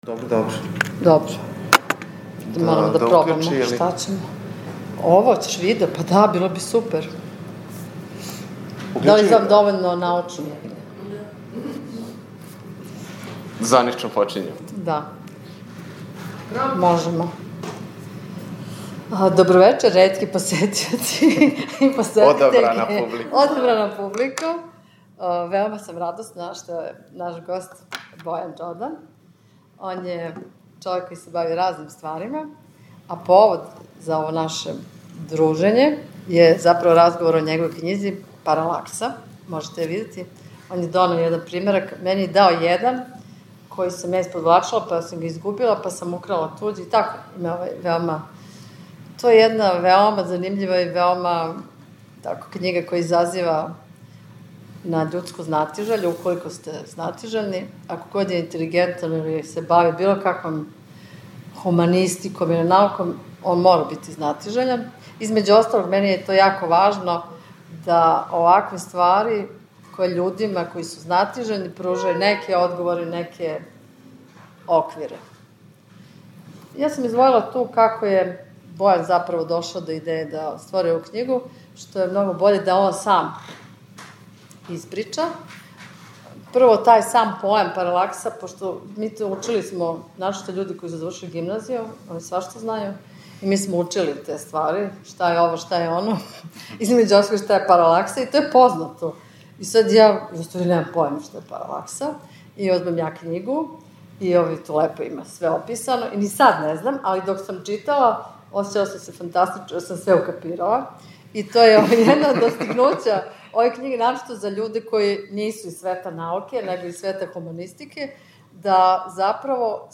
Трибина Српског књижевног друштва